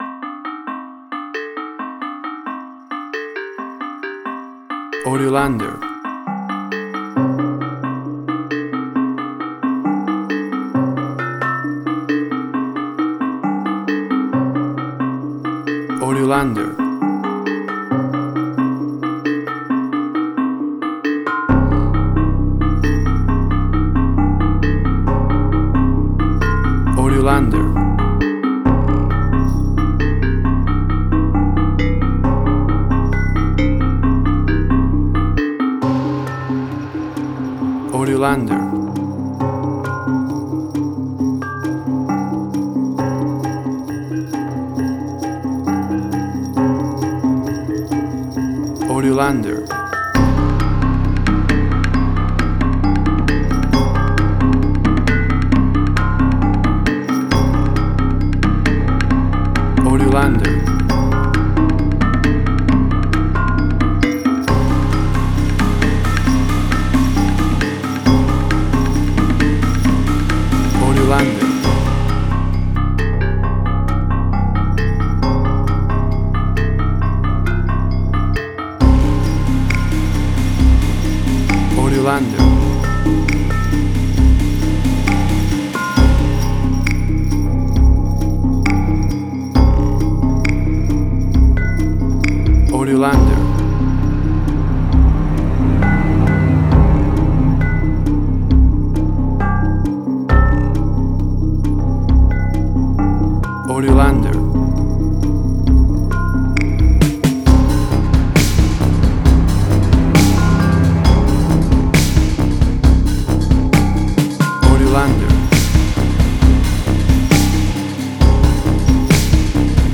Gamelan Ethnic instrumental
Tempo (BPM): 67